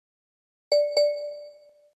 В подборке — короткие и узнаваемые сигналы, которые помогут настроить мессенджер под ваш стиль.
Звук вайбера оригинальный